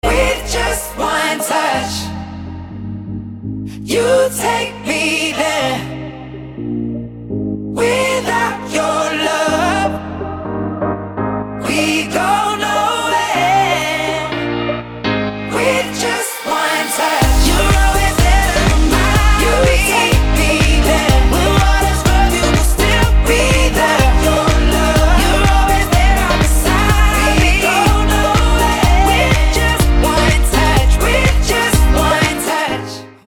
• Качество: 320, Stereo
поп
громкие
женский вокал
dance
нарастающие
клавишные